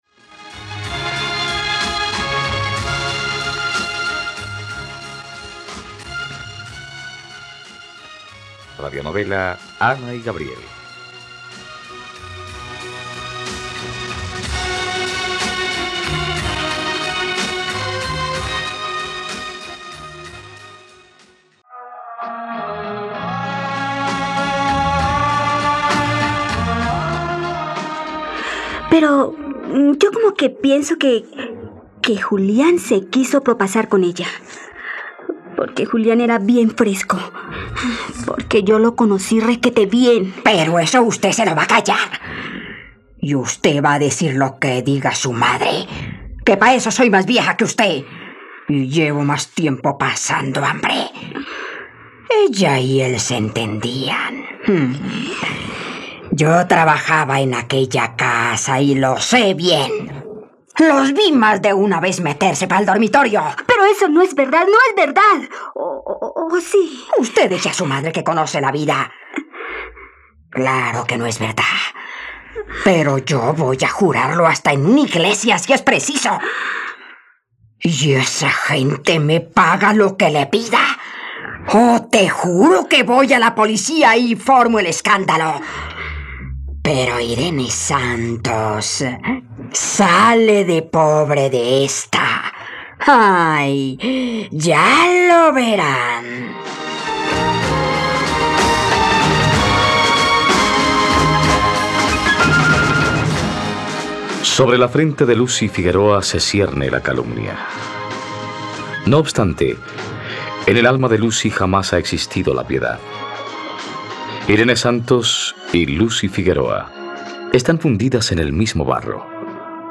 ..Radionovela. Escucha ahora el capítulo 98 de la historia de amor de Ana y Gabriel en la plataforma de streaming de los colombianos: RTVCPlay.